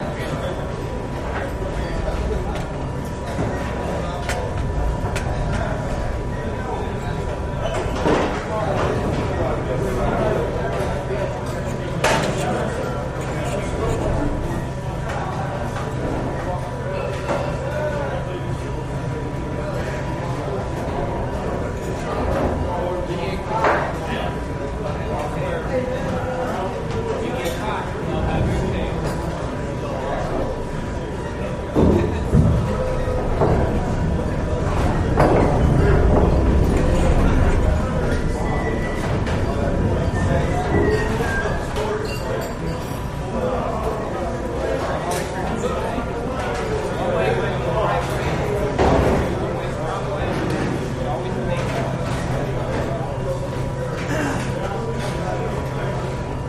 Mess Hall Enlisted Mess Of Carrier, Looped